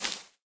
Minecraft Version Minecraft Version snapshot Latest Release | Latest Snapshot snapshot / assets / minecraft / sounds / block / cobweb / break3.ogg Compare With Compare With Latest Release | Latest Snapshot